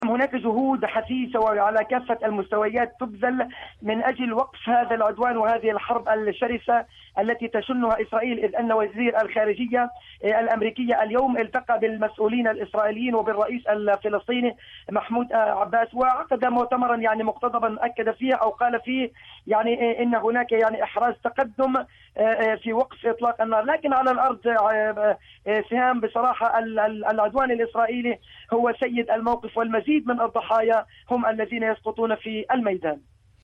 مراسلة